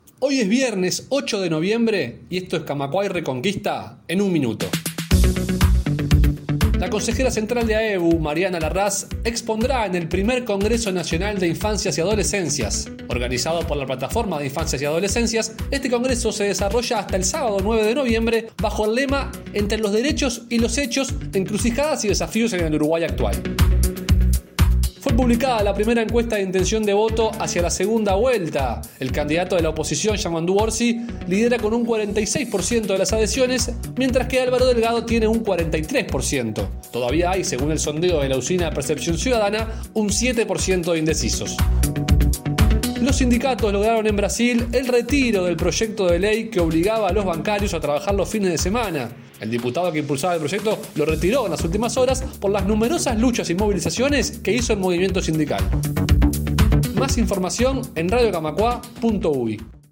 Las noticias del día en 1'